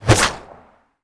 bullet_leave_barrel_04.wav